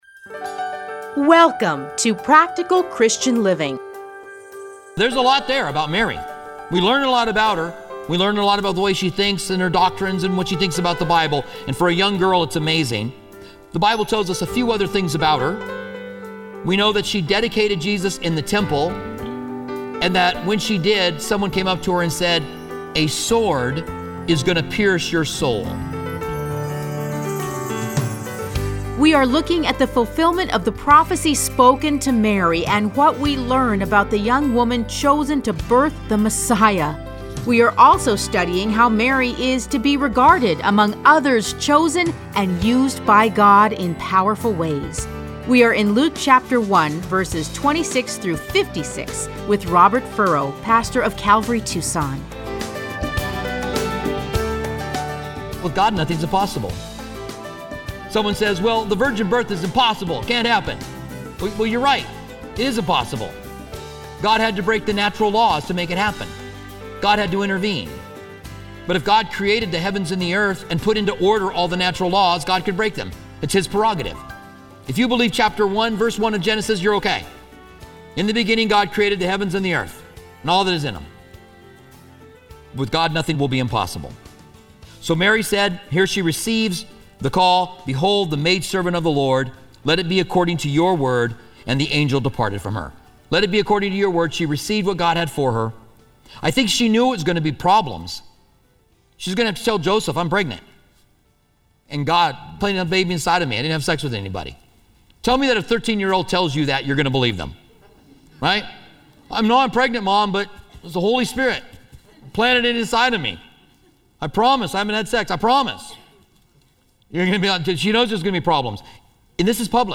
Listen to a teaching from Luke 1:26-56.